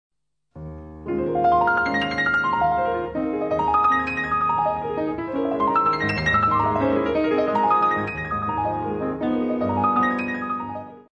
Comme la plupart des études de Chopin, celle-ci est consacrée à un problème particulier de la technique pianistique: ici les arpèges pour la main droite.
Toute l'étude est basée sur un formule d'arpèges brisés sur trois octaves.
1/ des accords syncopés divisant la mesure à 6/8 en une mesure à 3/4
2/ des arpèges évoluant soit en mouvement contraire soit en mouvement parallèle par rapport à la main droite.
L'intérêt de cette pièce réside essentiellement en son langage harmonique caractérisé par une grande liberté de modulations.